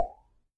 popup.wav